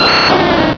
sovereignx/sound/direct_sound_samples/cries/ivysaur.aif at master